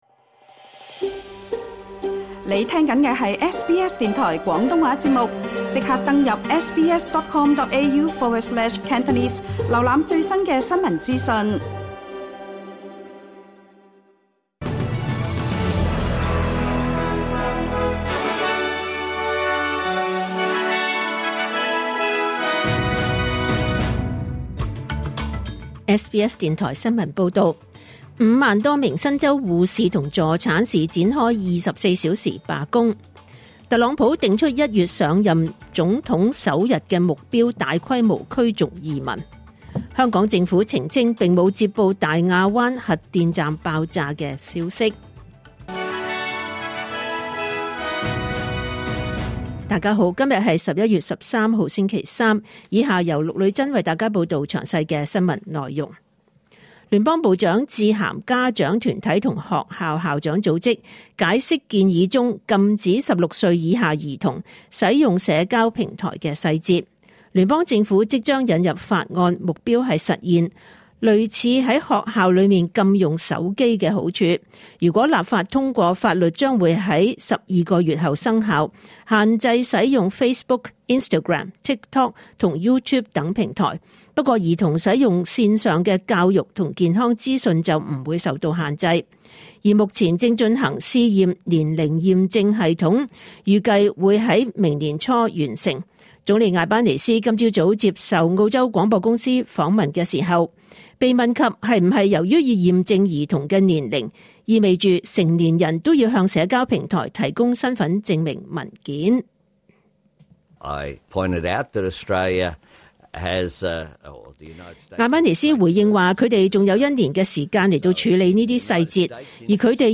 2024 年 11 月 13 日 SBS 廣東話節目詳盡早晨新聞報道。